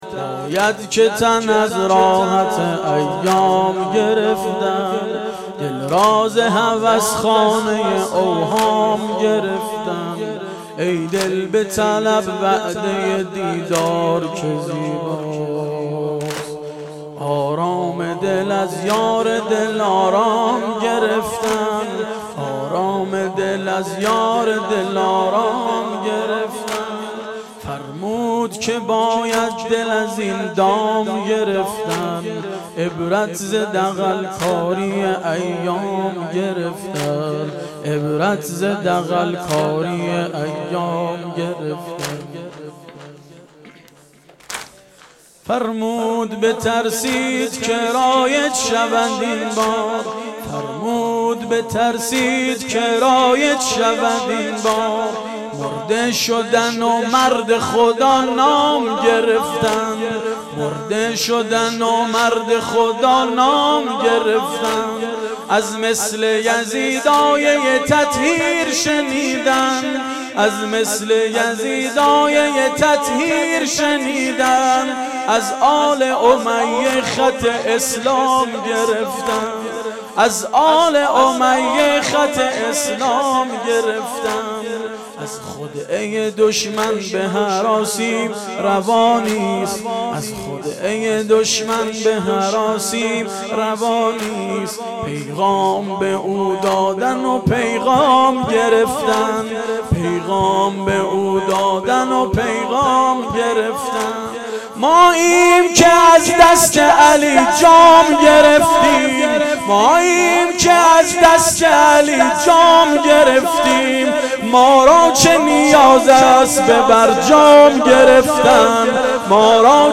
واحد تند شب عاشورا98